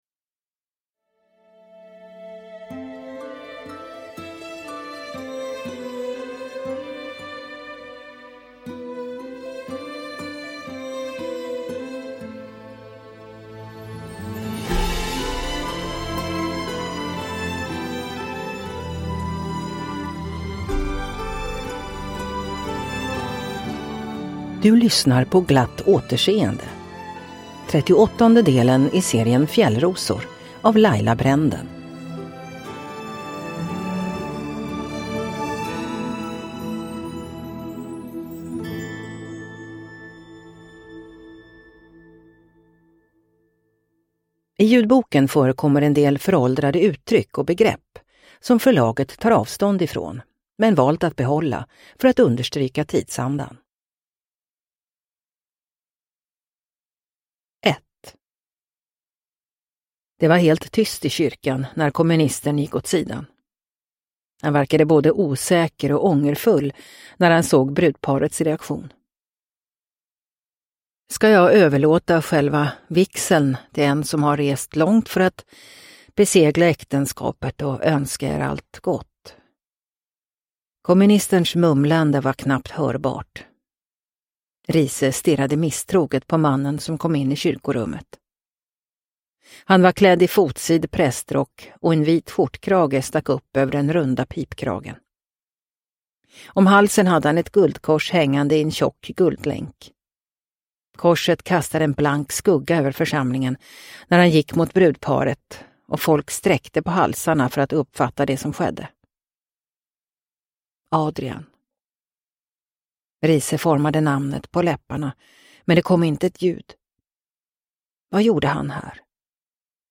Glatt återseende – Ljudbok – Laddas ner